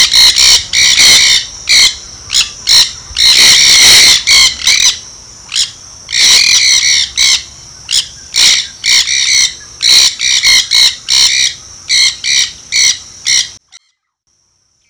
Nandayus nenday - Loro cabeza negra
VOZ Las llamadas incluyen kree-ah kree-ah y krehh y chr ■ chriie chriie, esta última similar a la voz de White-eyed Conure.
lorocabezanegra.wav